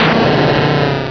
Cri de Granbull dans Pokémon Rubis et Saphir.
Cri_0210_RS.ogg